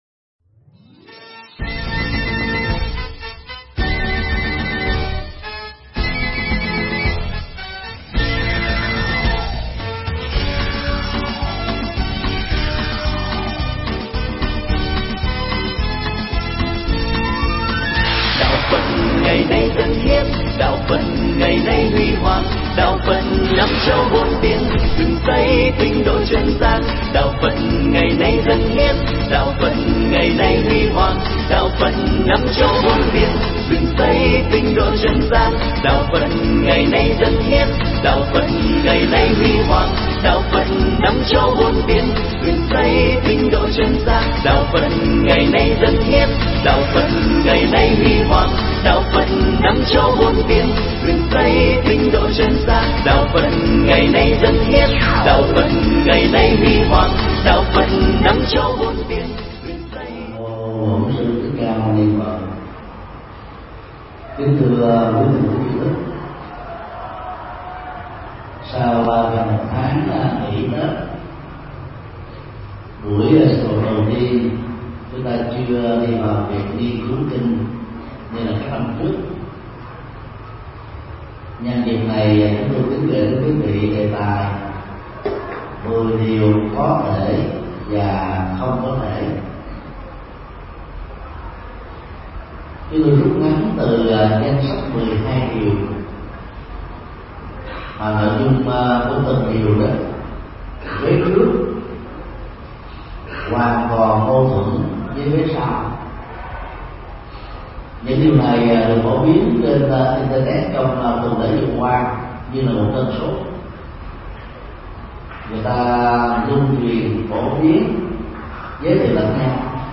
Tải mp3 Pháp thoại 10 Điều Có Thể và Không Thể Làm Được do thầy Thích Nhật Từ giảng tại chùa Xá Lợi, ngày 17 tháng 03 năm 2013